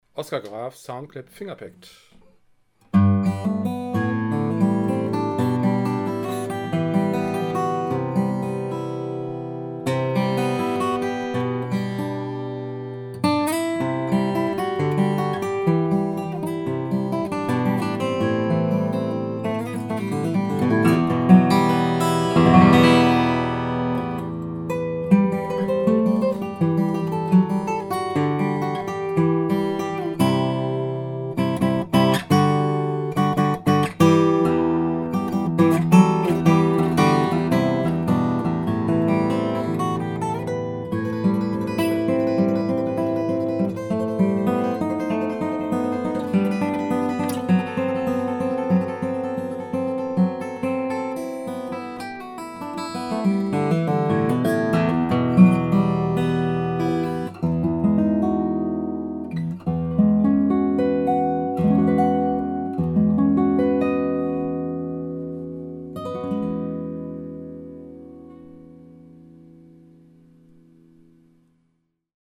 Extrem ausgeglichener und nuancenreicher Ton.
Fingerstyle
Boden und Zargen: sehr stark geflammtes Koa
Decke: Tiroler Fichte